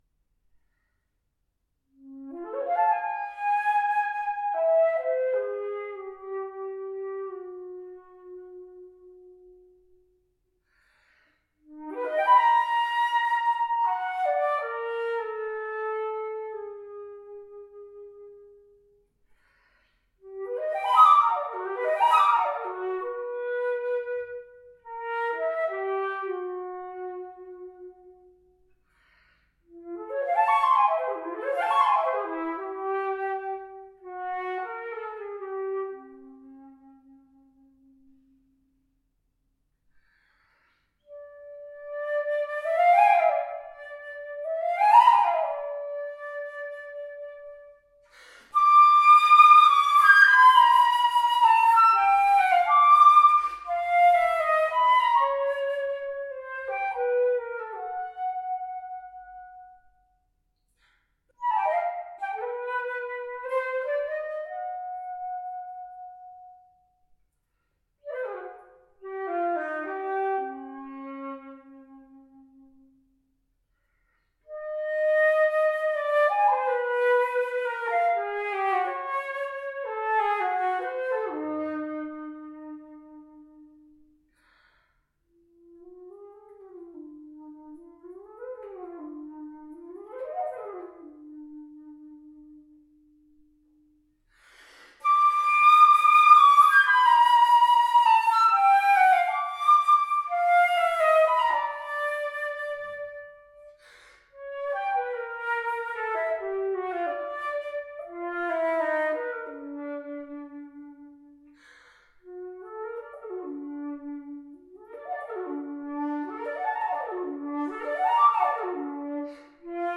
Silver flute – Rudall Carte & Co Ltd.
Description Vintage solid silver flute by Rudall Carte & Co Ltd.
The key cups are nicely shallow and give the flute its delicate style.
Pitched at a=442 Hz, the sound is powerful, round and warm – rather to be expected from a fine English flute indeed!